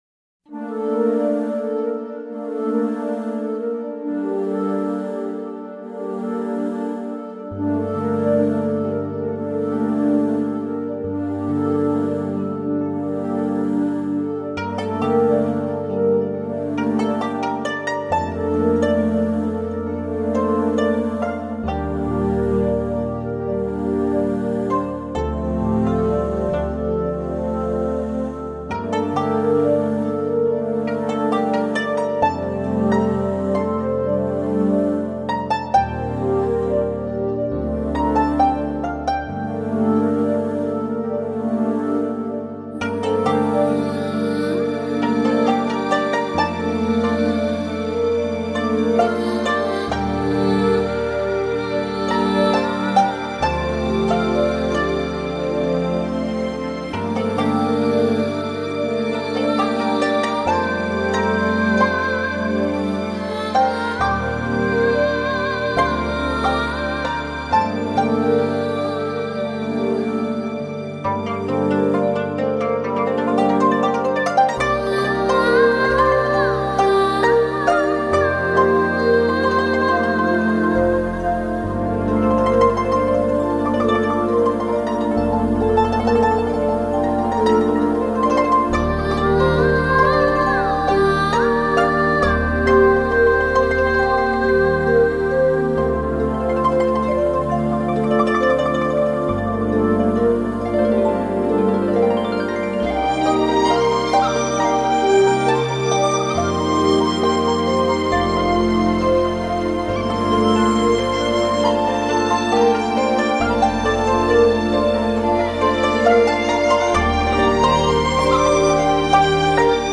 （陪伴/扬琴） 激动社区，陪你一起慢慢变老！